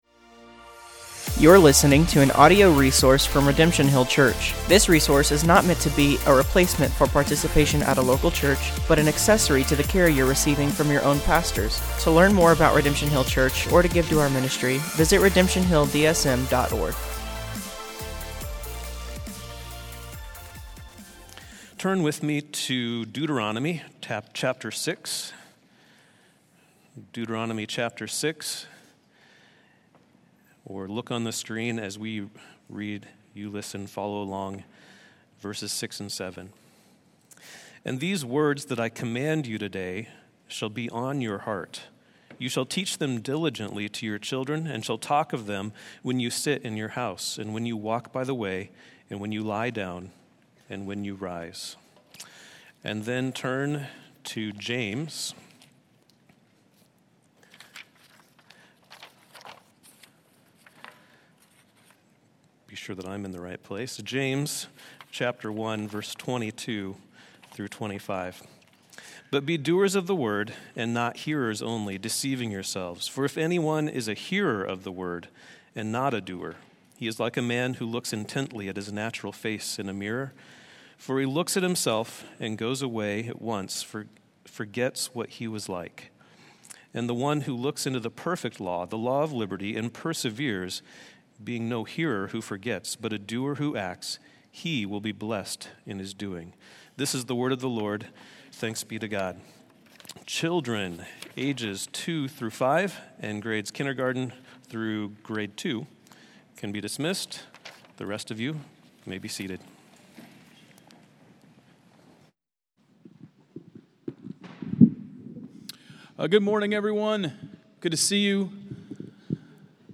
A message from the series "Stand Alone." Psalm 100 is about worship. It is about worshiping God with a thankful heart.